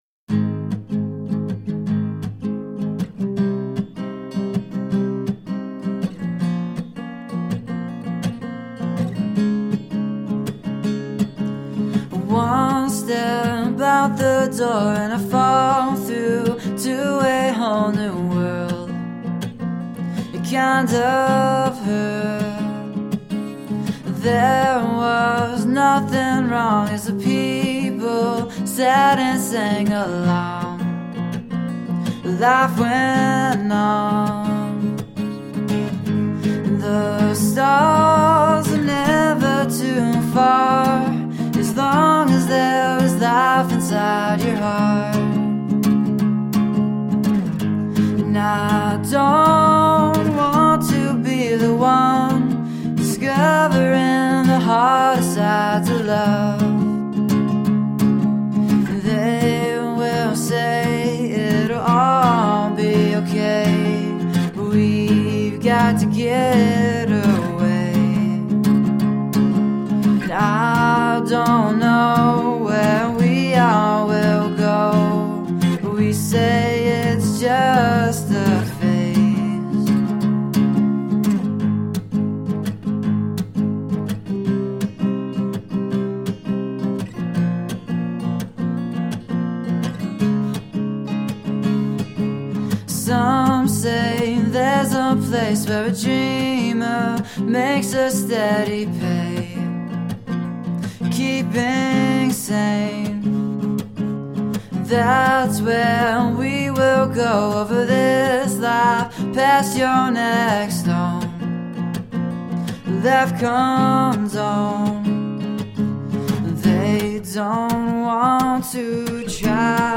Guitar driven alternative rock.
Tagged as: Alt Rock, Folk-Rock, Chillout, Indie Rock